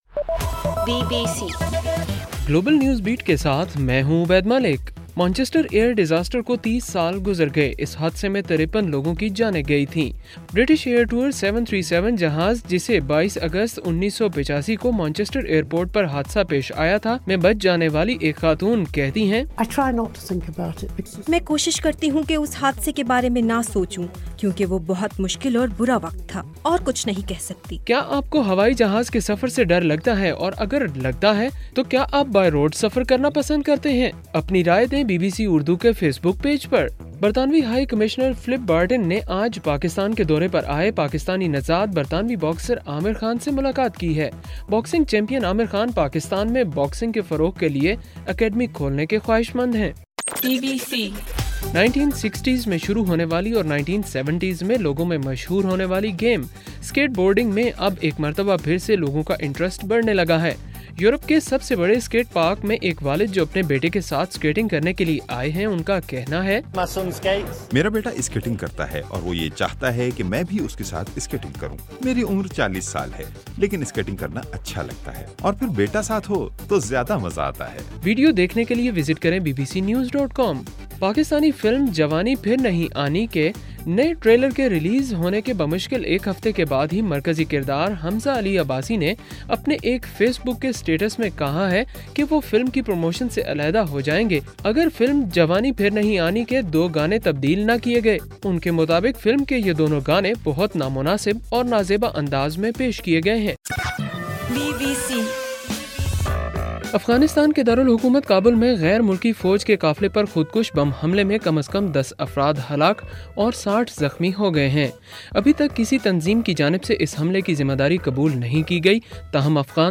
اگست 22: رات 10 بجے کا گلوبل نیوز بیٹ بُلیٹن